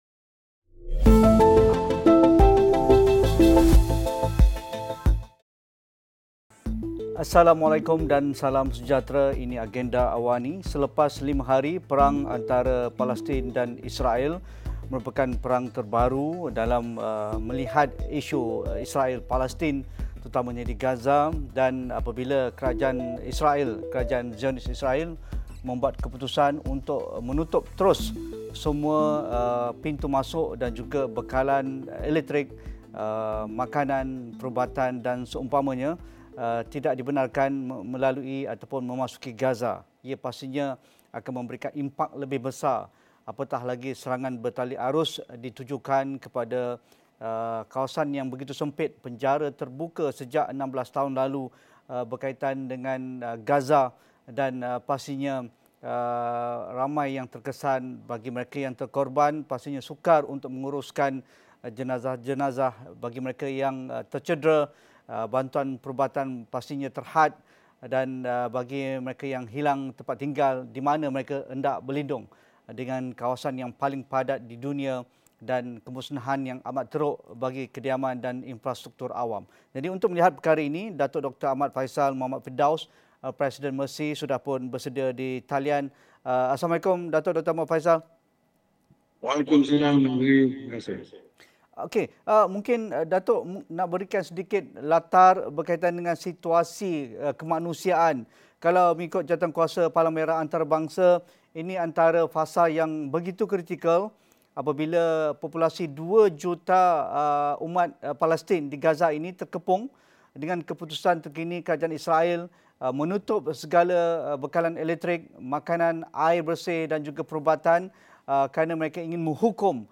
Situasi perang Palestin-Israel semakin memburuk. Namun, sejauh mana diplomasi kecemasan di peringkat antarabangsa dapat diperkukuh untuk menggerakkan misi bantuan kemanusiaan bagi mangsa yang terkesan akibat perang? Diskusi 8.30 malam